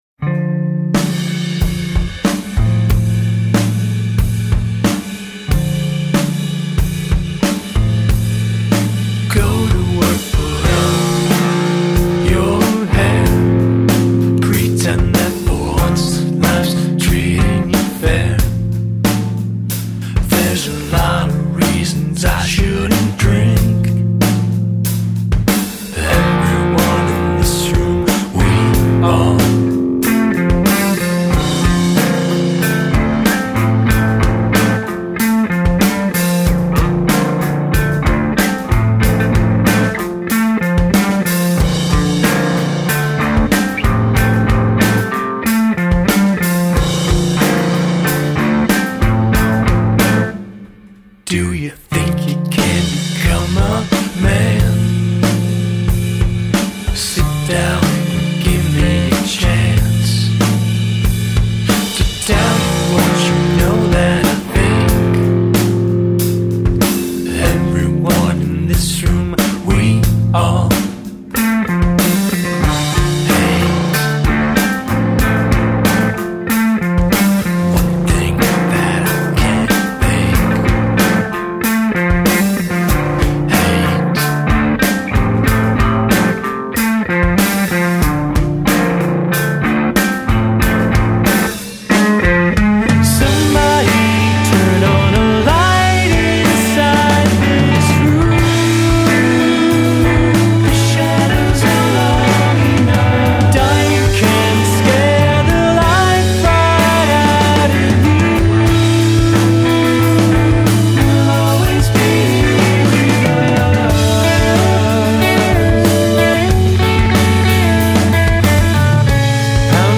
somewhere between indie pop and post-rock